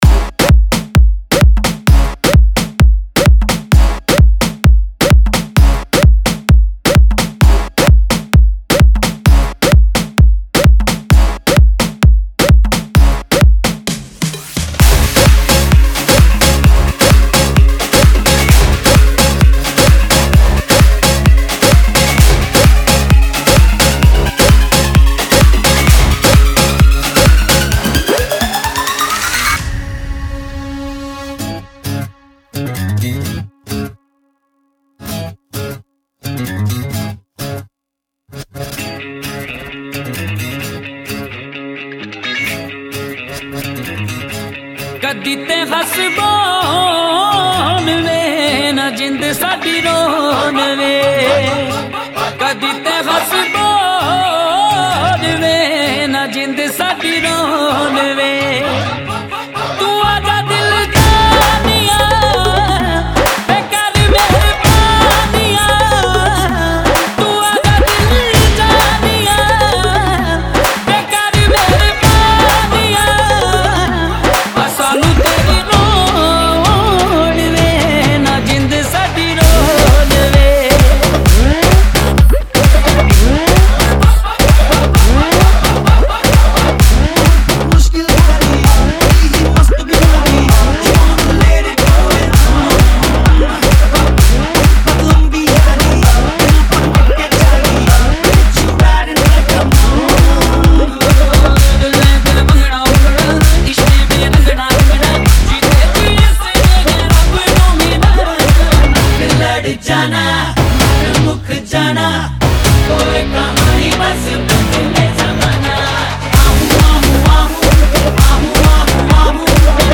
WEDDING DESI REMIX